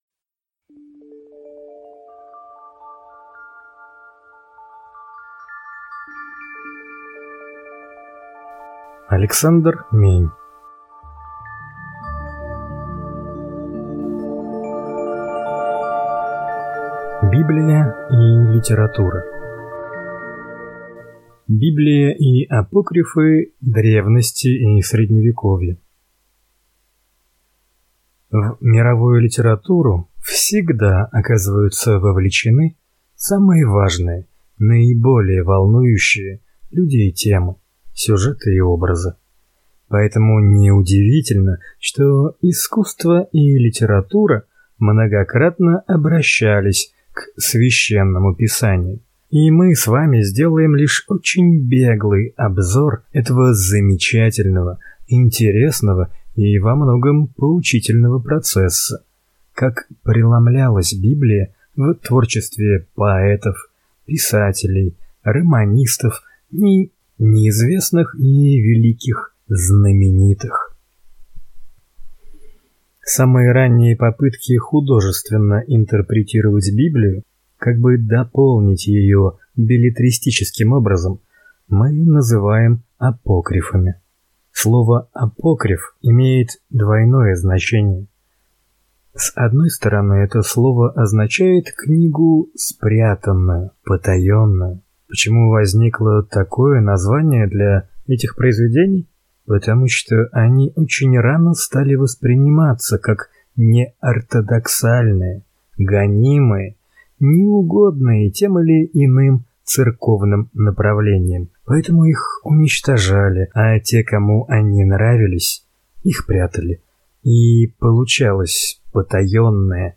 Аудиокнига Библия и литература | Библиотека аудиокниг
Прослушать и бесплатно скачать фрагмент аудиокниги